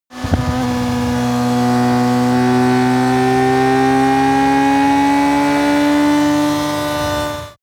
Gemafreie Sounds: Motorräder